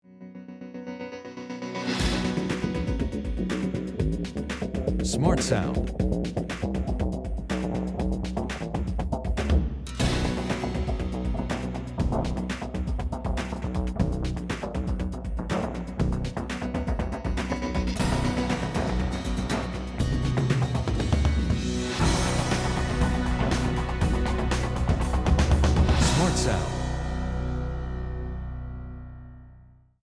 Will you listen to several short pieces of background music that we are considering using in a 30 second trailer/advert for the pilot programme?